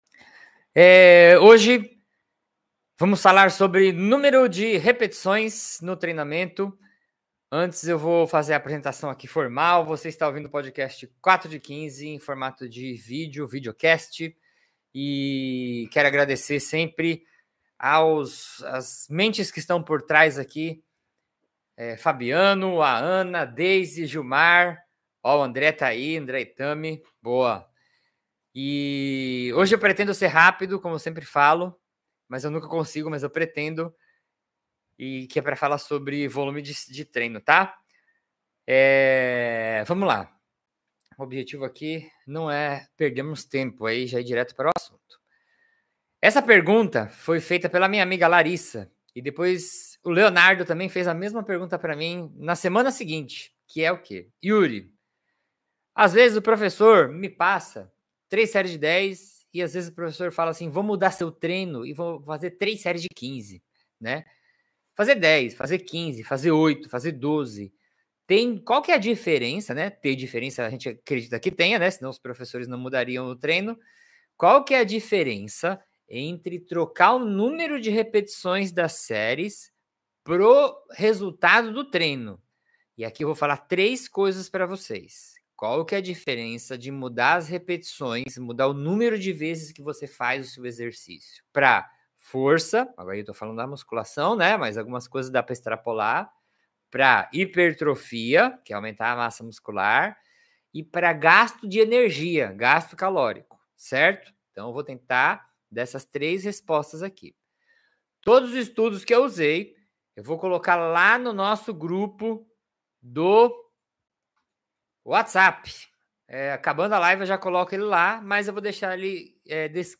Nesta live, vamos desmistificar essa dúvida comum com informações práticas e baseadas em ciência! Descubra qual estratégia se encaixa melhor no seu objetivo: hipertrofia ou força. Entenda como o volume e a intensidade dos exercícios impactam nos seus resultados.